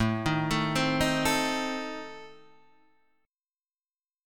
A Diminished